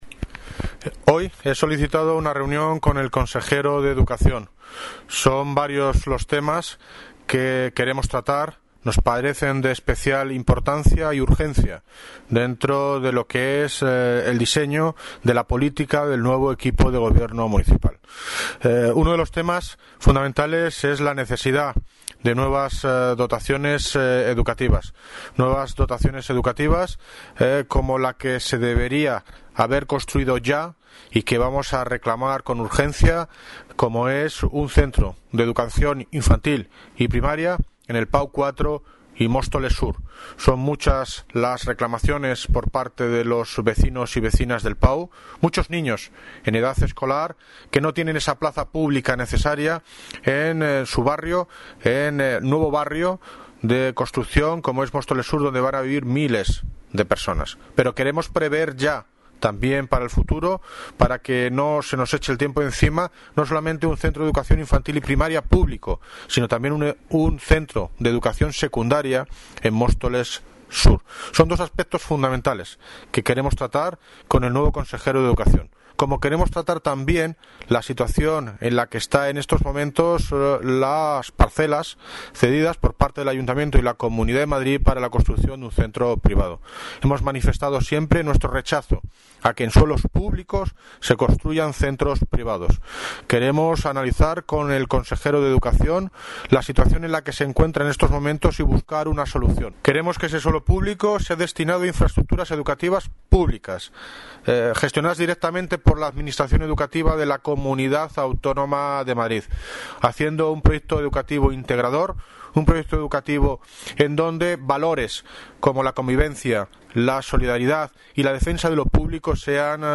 Audio - David Lucas (Alcalde de Móstoles) Sobre solicitud reunión Consejería de Educación